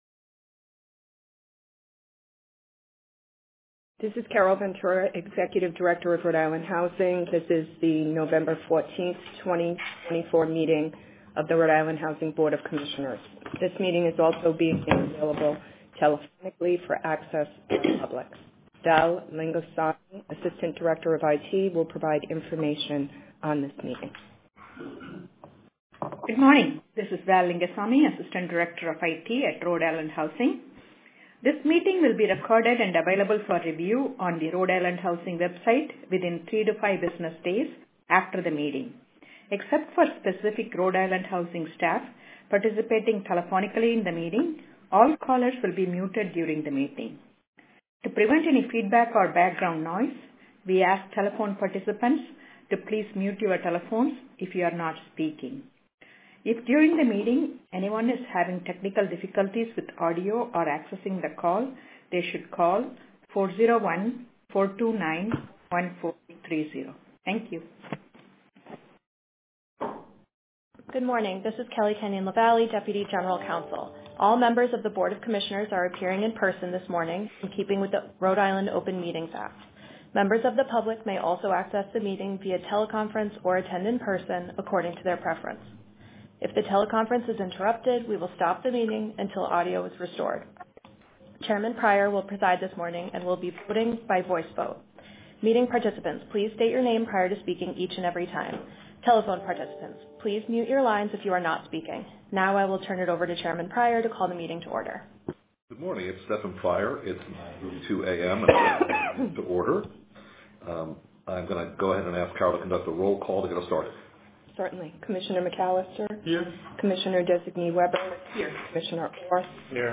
Recording of RIHousing Board of Commissioners Meeting: 11.14.2024